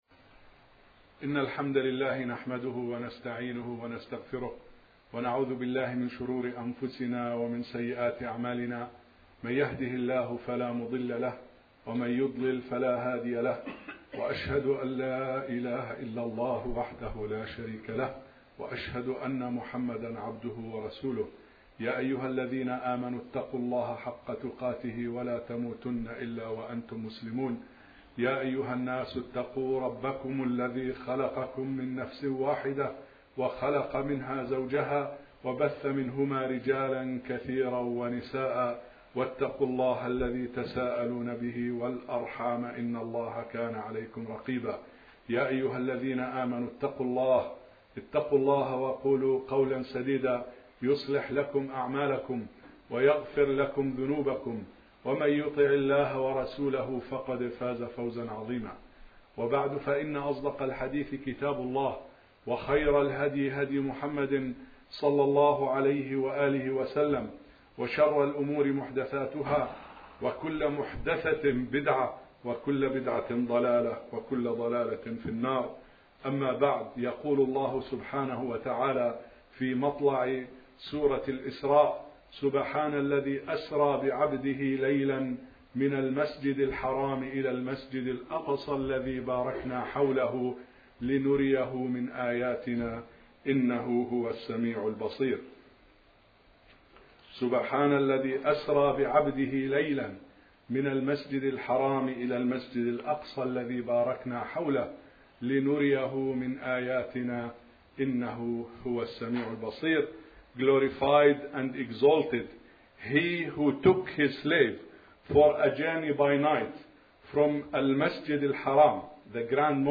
To hear this informative audio khutbah, please click on he link below: